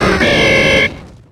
Cri de Galifeu dans Pokémon X et Y.